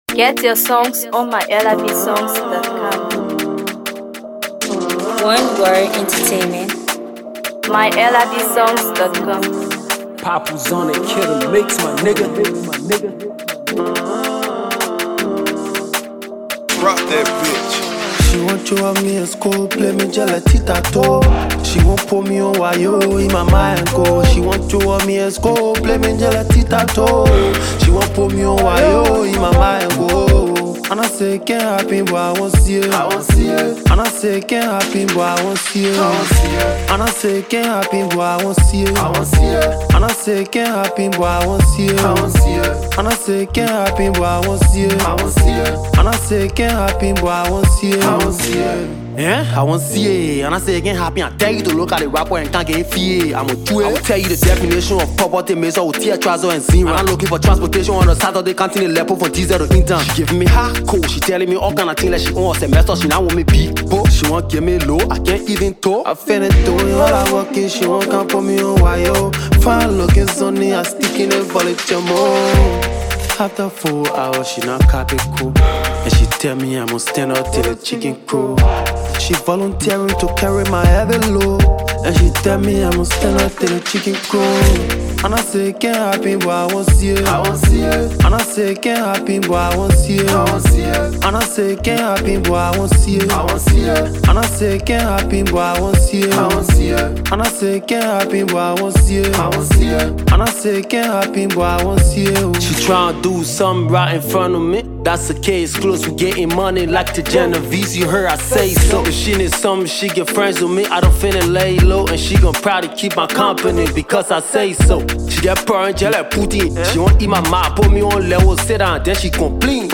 Hip HopHipcoMusic